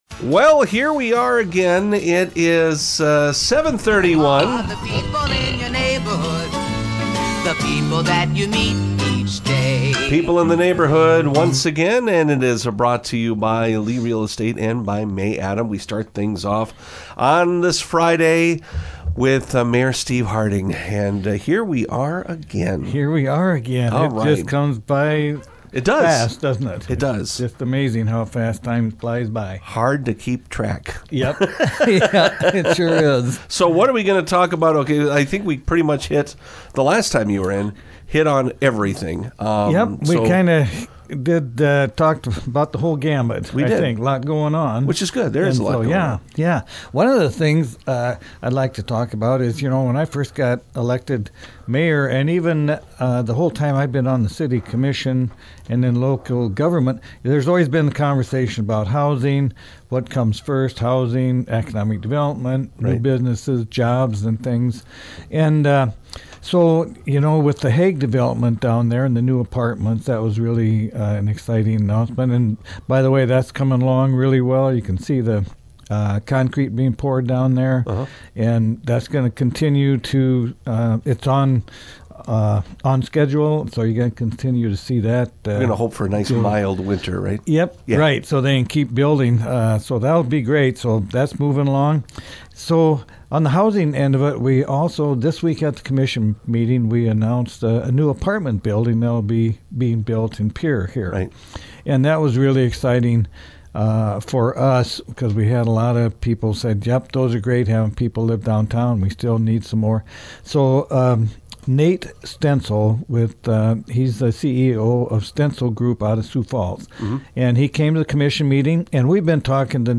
chatted with Pierre Mayor Steve Harding